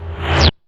001 BreakSnr2Rvs.wav